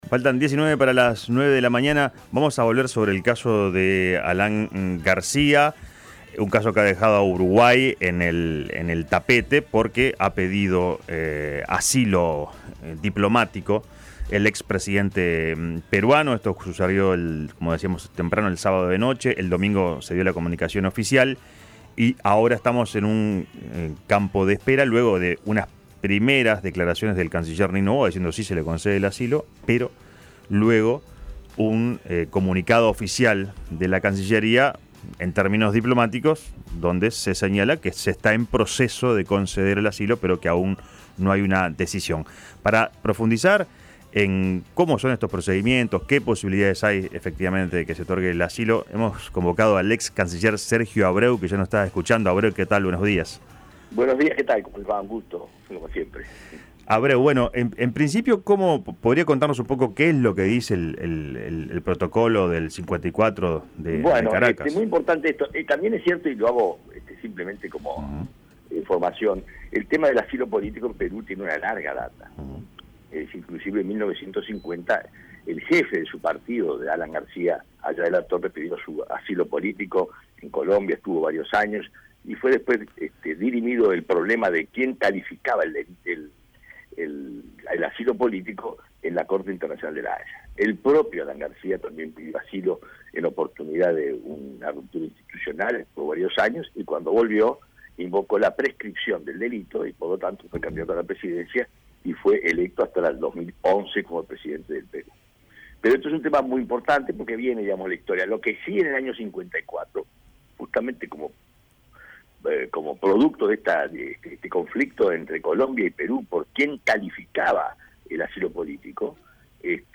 El nacionalista sostuvo en La Mañana de El Espectador que no quisiera estar en estos momentos en los zapatos del gobierno uruguayo para tomar una decisión ya que para conceder el asilo o rechazarlo se debe analizar hasta qué punto los delitos que se le adjudican a García están relacionados con una persecución política.